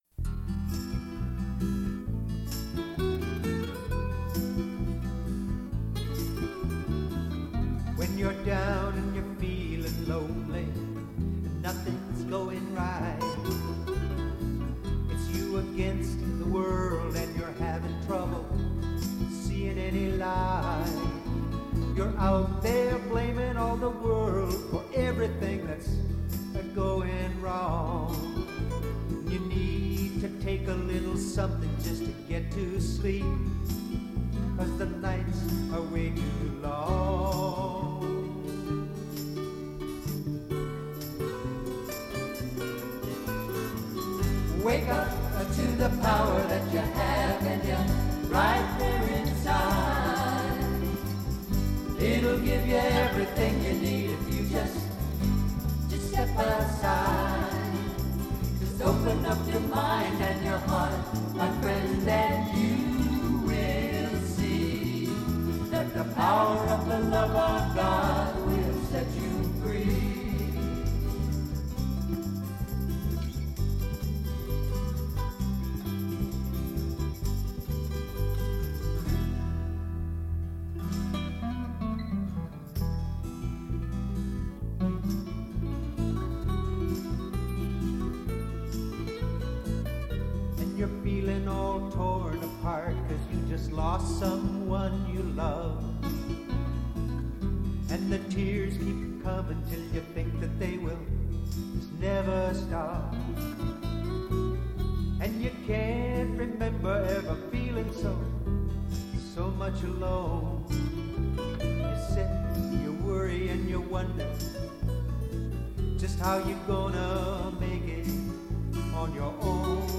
1. Devotional Songs
Minor (Natabhairavi)
8 Beat / Keherwa / Adi
4 Pancham / F
1 Pancham / C